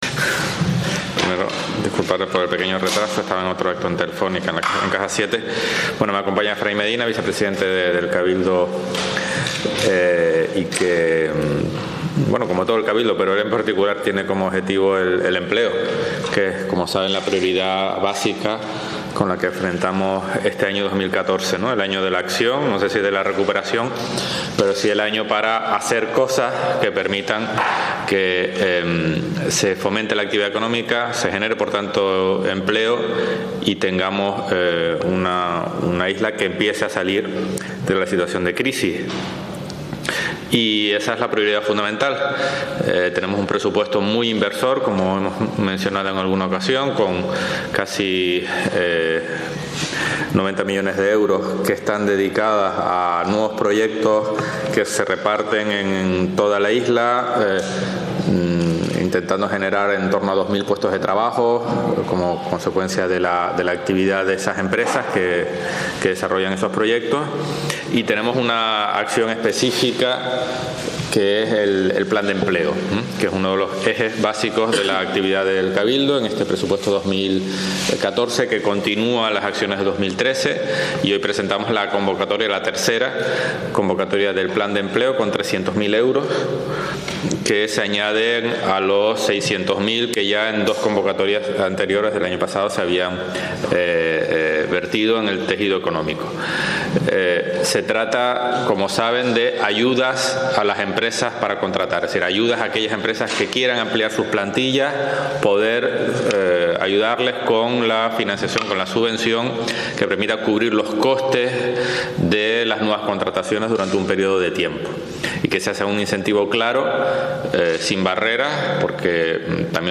Canal Tenerife TV | Rueda de prensa para anunciar la segunda convocatoria de subvenciones del Plan de Acción por el Empleo de Tenerife
Con la participación del presidente del Cabildo, Carlos Alonso, y el vicepresidente económico y consejero de...